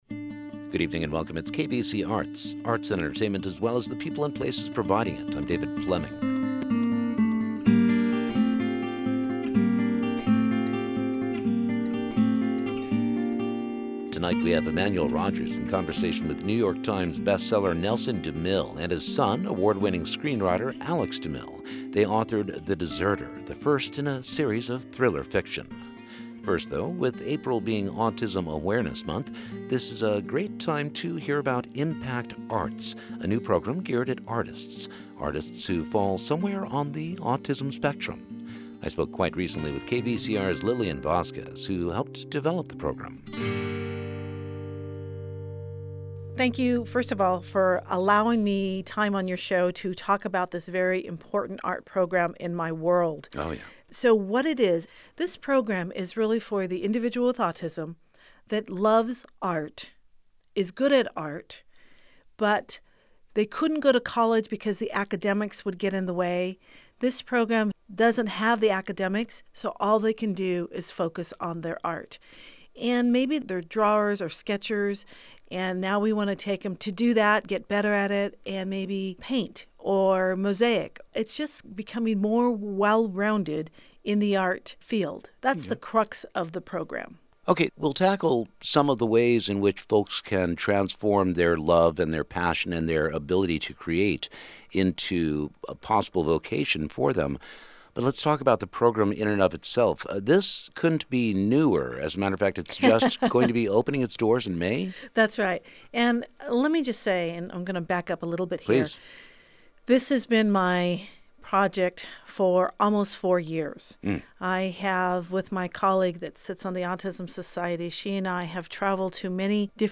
Interviews with authors, producers, visual artists and musicians, spotlighting a classic album or a brand new release. From musical legends and icons - to players at the local level, from Broadway tours to Community theater, from the Hollywood Bowl to the Redlands Bowl.